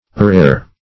urare - definition of urare - synonyms, pronunciation, spelling from Free Dictionary
Urare \U*ra"re\